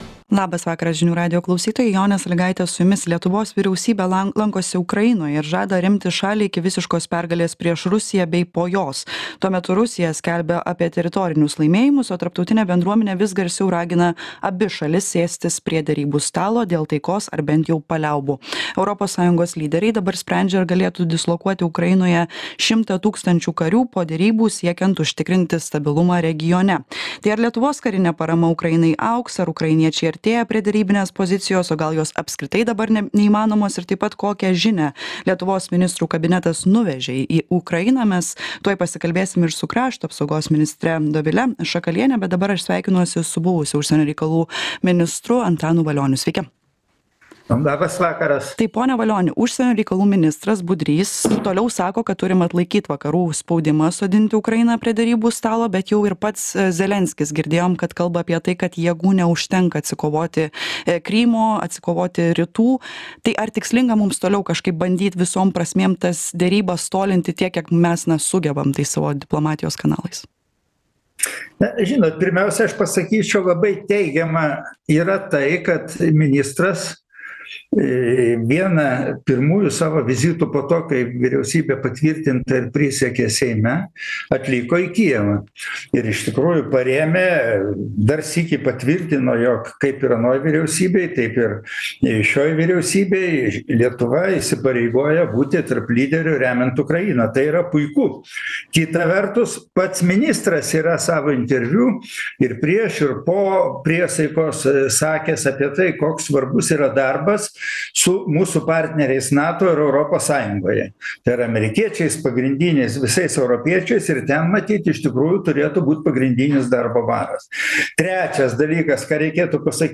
Laidoje dalyvauja krašto apsaugos ministrė Dovilė Šakalienė ir buvęs užsienio reikalų ministras Antanas Valionis.
Aktualusis interviu